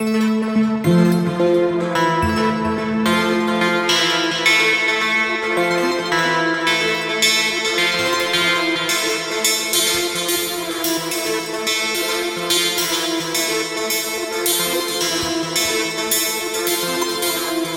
描述：y 拨动的琴弦
标签： 108 bpm Hip Hop Loops Sitar Loops 2.99 MB wav Key : Unknown
声道立体声